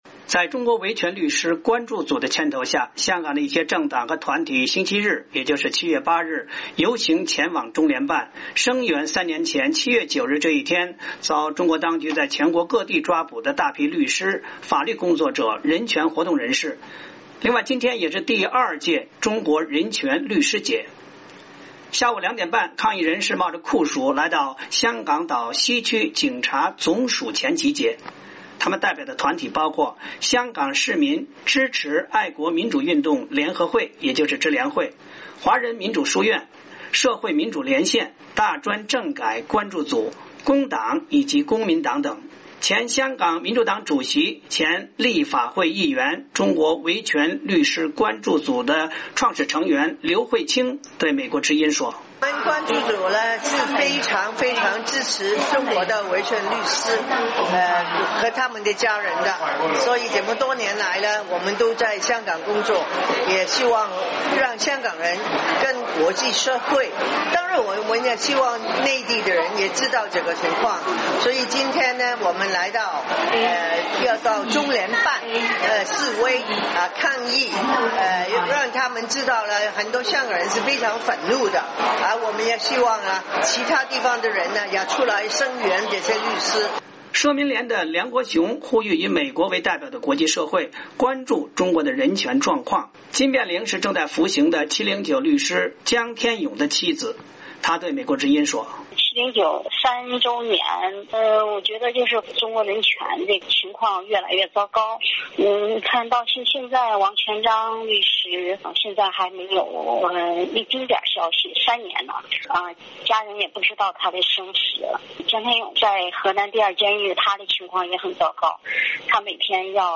抗议示威
下午两点半，抗议人士冒酷暑来到香港岛西区警察总署前集结。